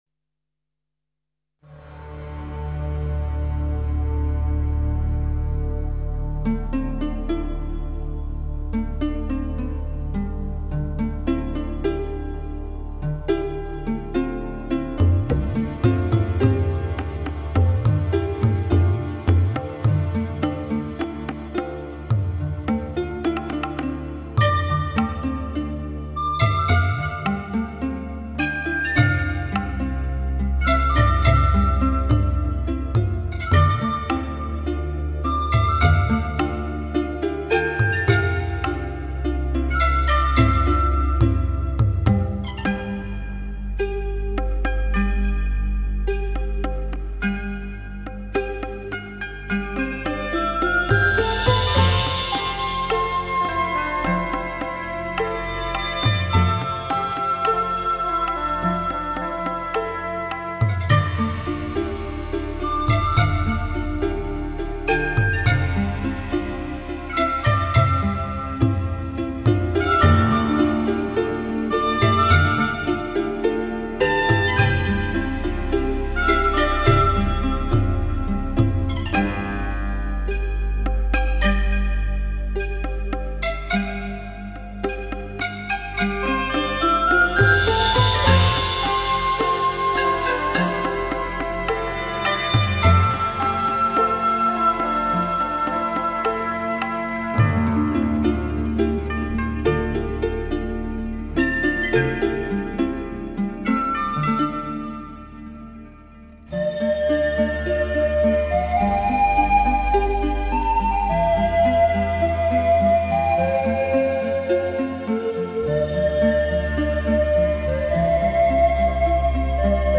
旋律简单纯净，编曲精致利落，如同最永恒的情感—细腻、温柔，情感在音符的跳跃间逐步释放